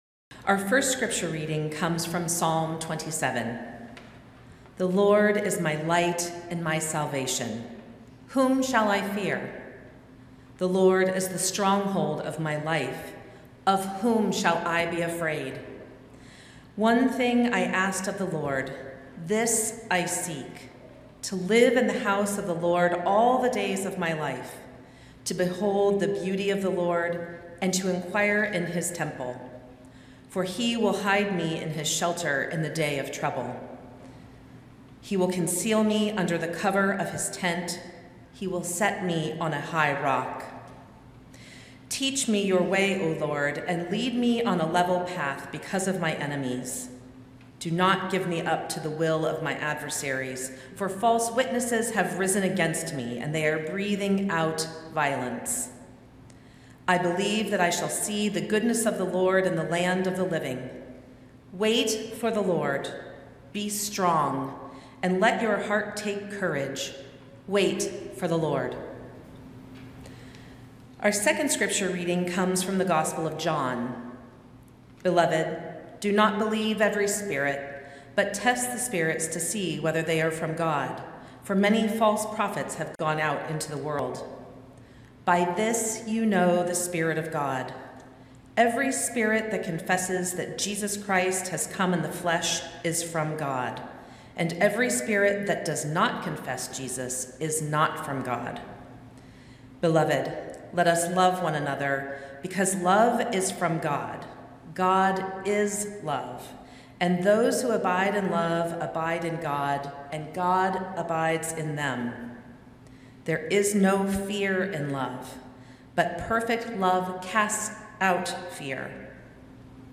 Sermon-Feb-8-2026-Institutional-Reckoning.mp3